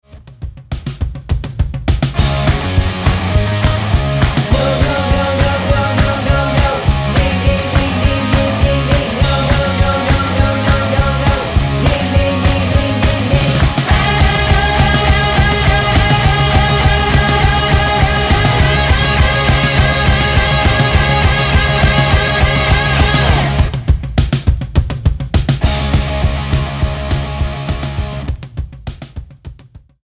--> MP3 Demo abspielen...
Tonart:B-C# mit Chor